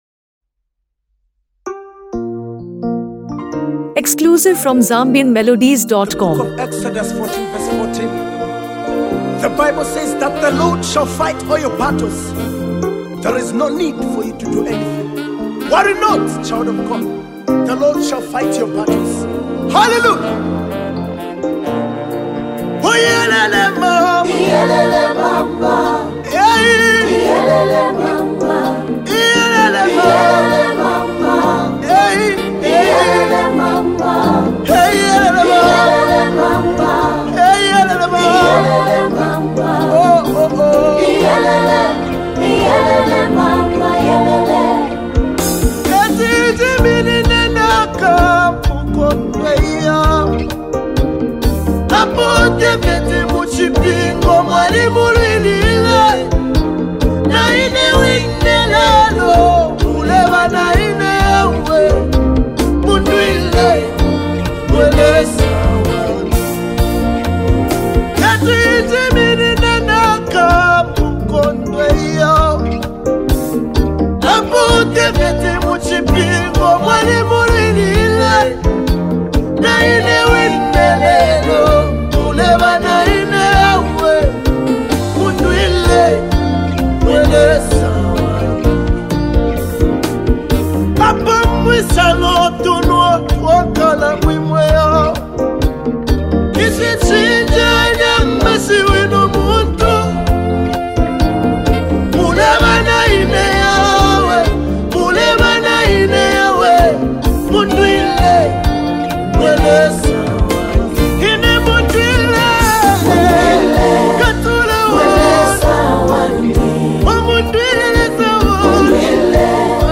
an uplifting spiritual anthem